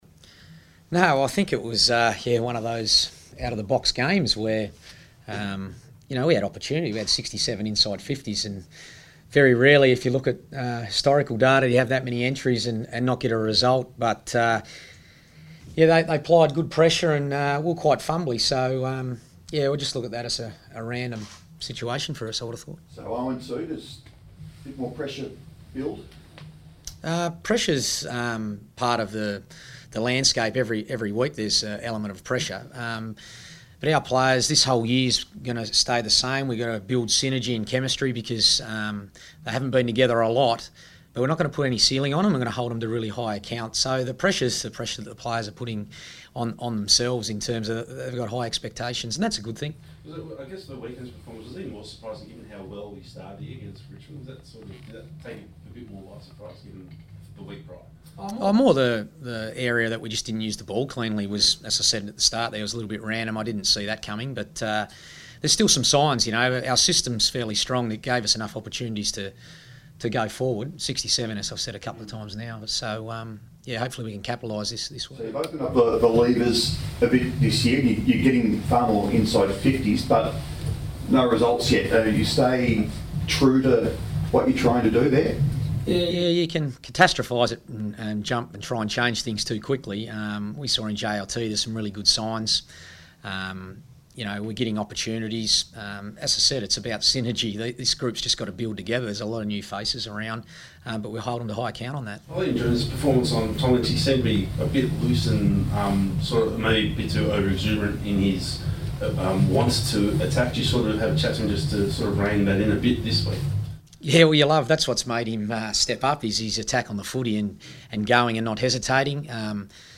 Brendon Bolton press conference | April 5
Carlton coach Brendon Bolton fronts the media on the eve of the Blues' Round 3 match against Collingwood.